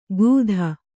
♪ gūdha